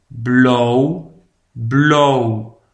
blauw PRONONCIATION